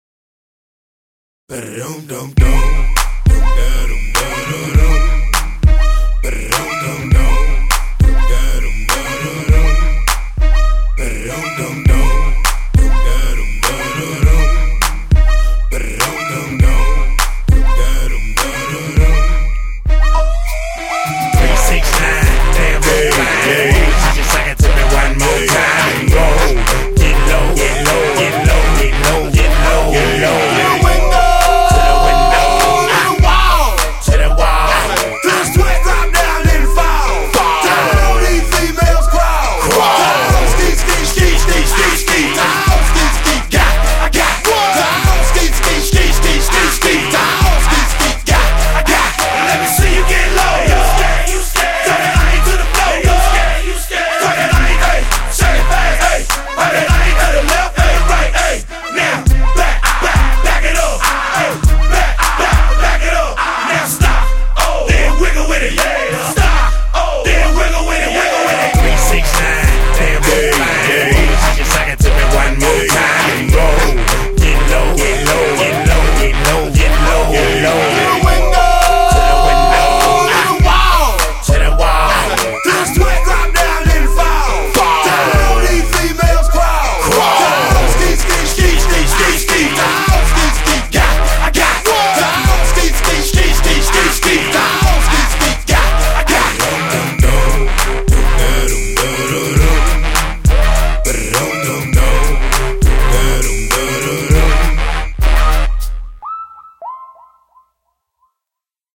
BPM101
Audio QualityPerfect (High Quality)